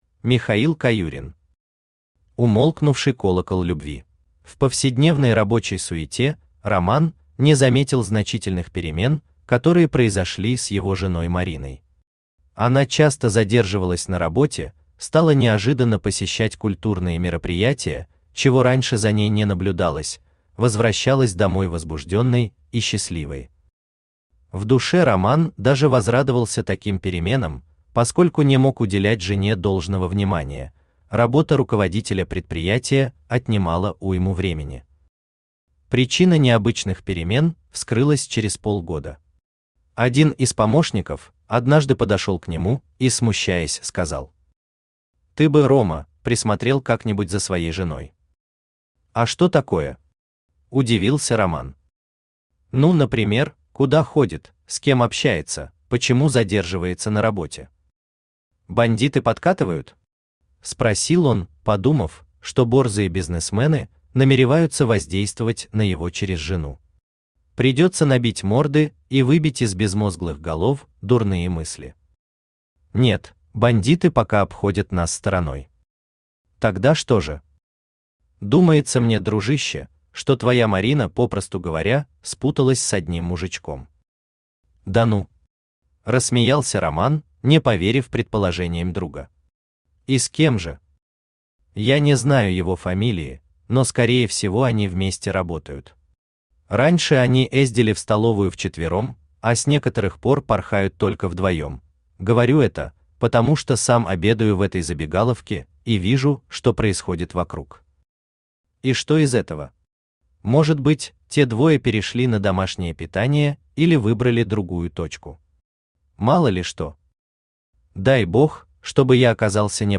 Аудиокнига Умолкнувший колокол любви | Библиотека аудиокниг
Aудиокнига Умолкнувший колокол любви Автор Михаил Александрович Каюрин Читает аудиокнигу Авточтец ЛитРес.